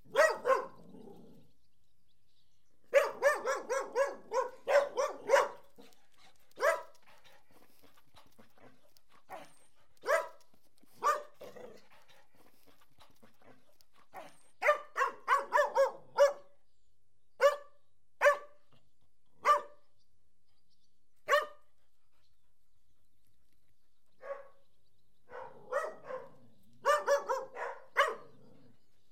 Dog_Barking-02.wav